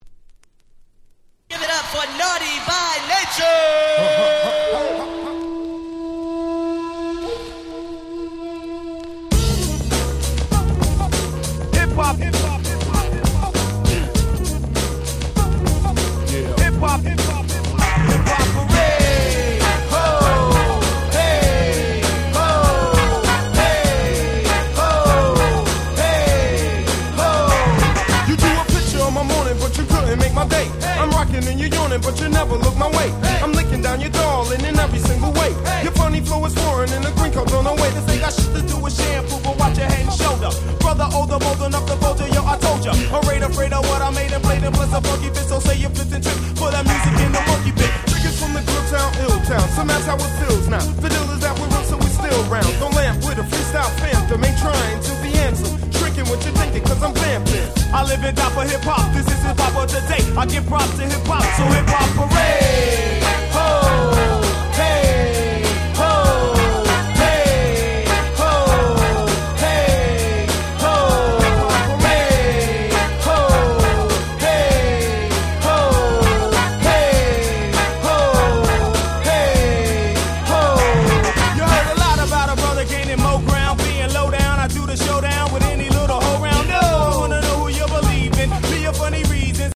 90's Boom Bap ブーンバップ